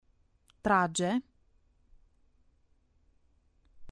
trage (cu arma)